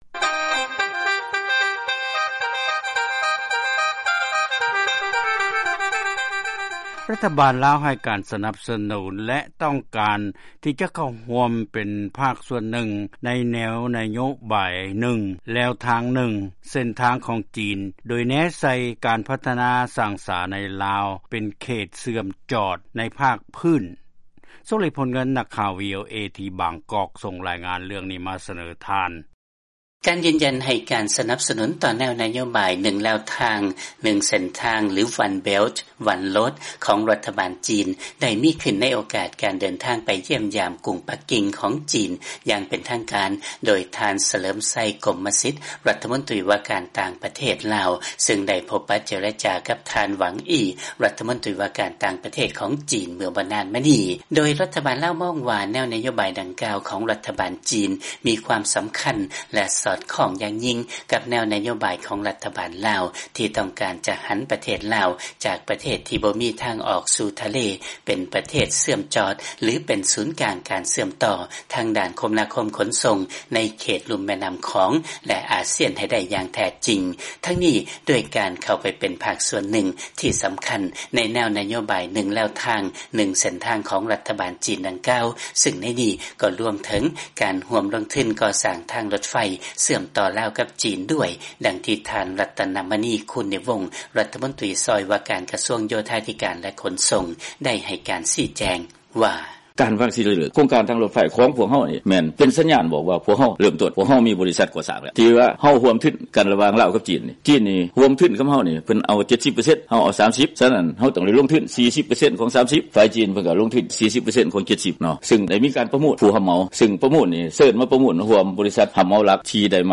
ຟັງລາຍງານ ລາວ ໃຫ້ການສະໜັບ ສະໜູນ ນະໂຍບາຍ ໜຶ່ງແລວທາງ ໜຶ່ງເສັ້ນທາງຂອງ ຈີນ